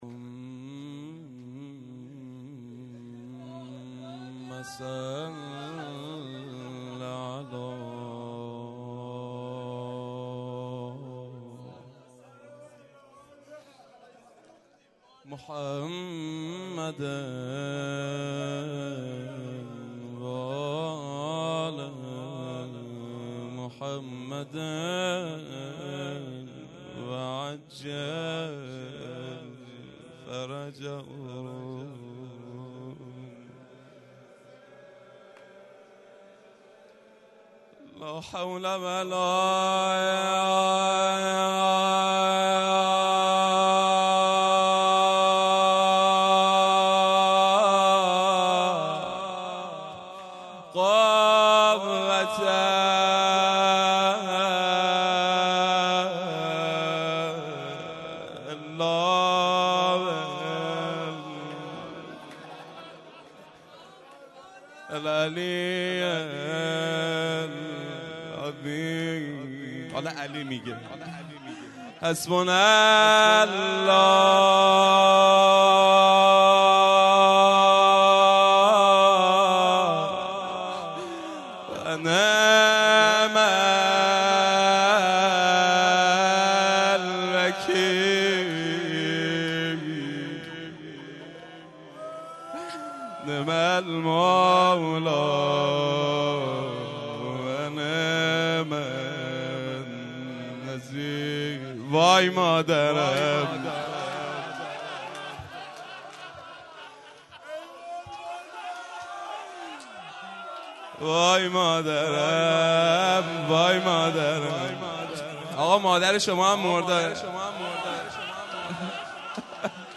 روضه شب شهادت